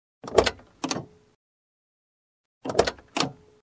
Звуки двери автомобиля